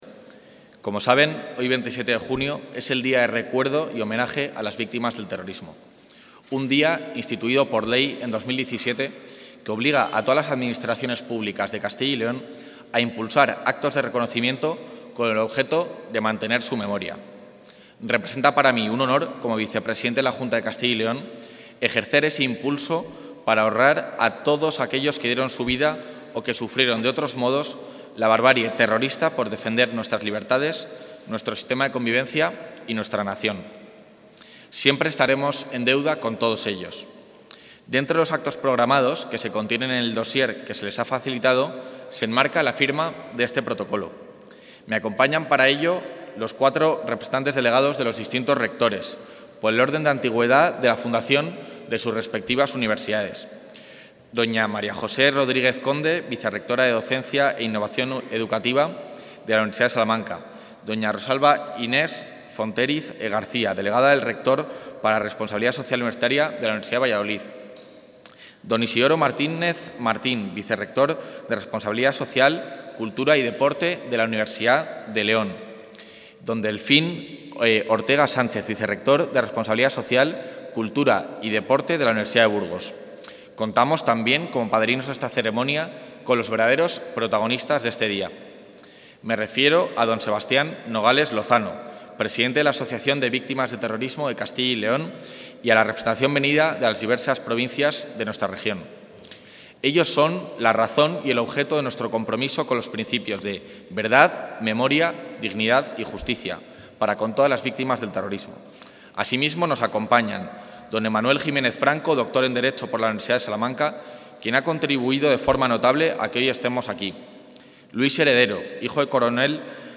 Intervención del vicepresidente.
El vicepresidente de la Junta, Juan García-Gallardo, ha participado este mediodía en la firma del protocolo con las universidades públicas de Castilla y León en materia de víctimas del terrorismo, con el que se pretende actuar conjuntamente en la consecución de los objetivos comunes del reconocimiento, la protección integral de las víctimas y la concienciación social frente al terrorismo en el ámbito de lo establecido en la Ley 4/2017, de 26 de septiembre, de Reconocimiento y Atención a las Víctimas del Terrorismo en Castilla y León.